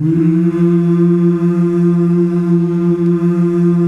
MMMMH   .1.wav